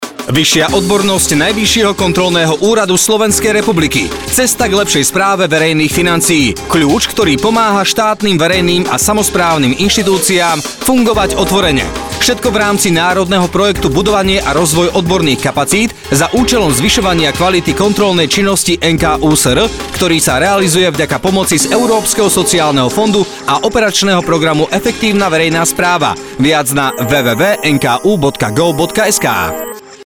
Radio spot (MP3, 1,1 MB)
fun-radio-spot-mp3